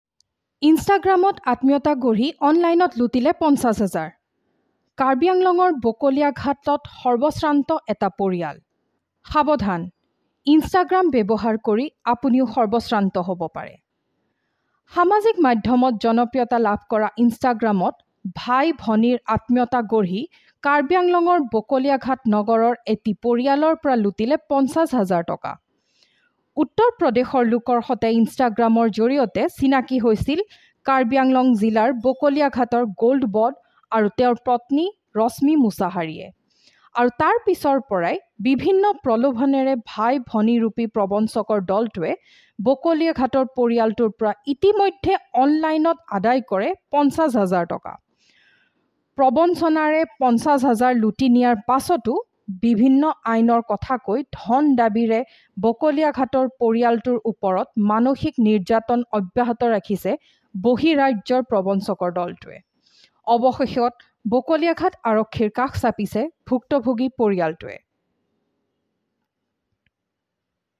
Professioal Female VO- ..